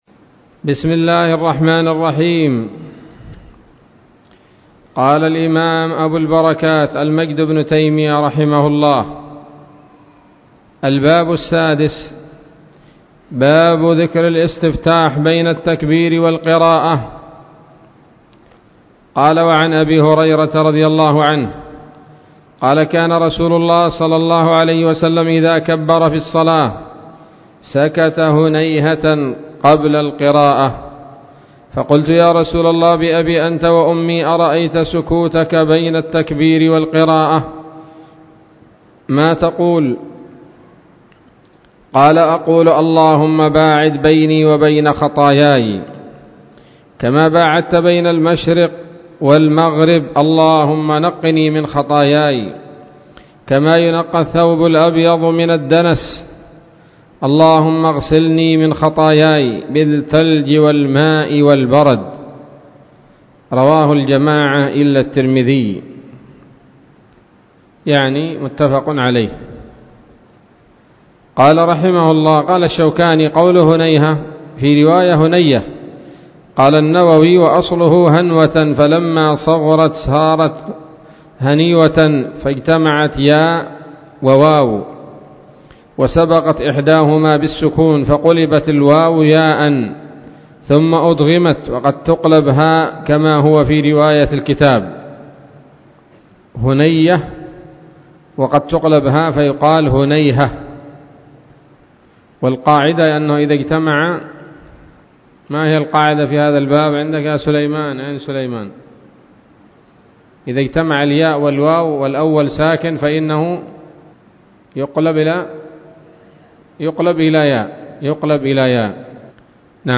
الدرس الخامس عشر من أبواب صفة الصلاة من نيل الأوطار